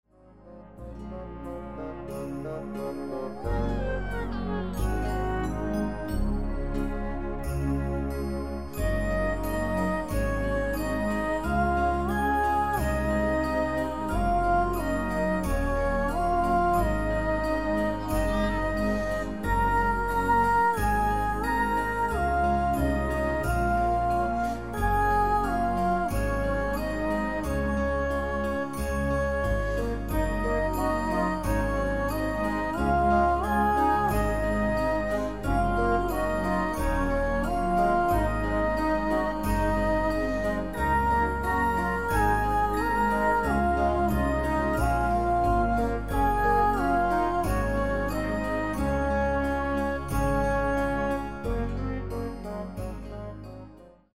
ének
karácsonyi dalok babáknak